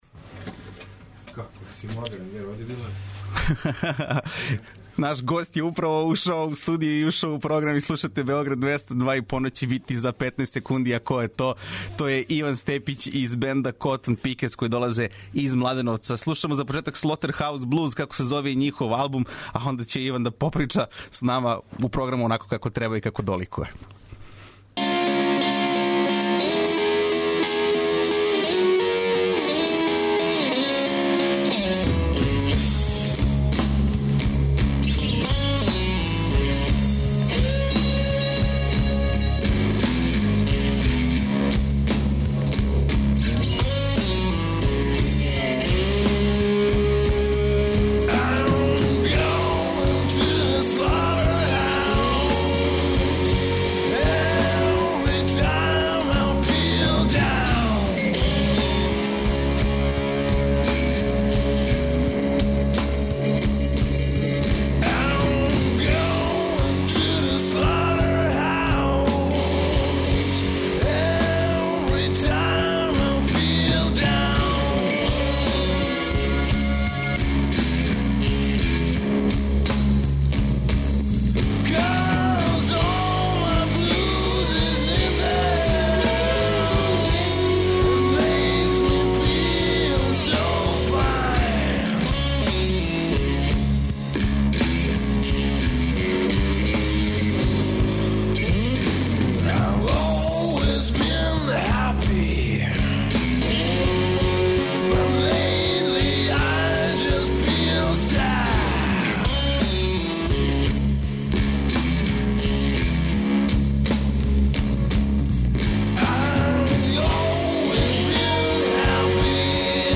Ноћас са нама младеновачки блузери - 'COTTON PICKERS'. Причаћемо о почецима, албуму, утицајима, наступу са Аном Поповић, плановима, а спремите се и за мало акустичне свирке уживо, коју ће нам приредити момци из ове групе.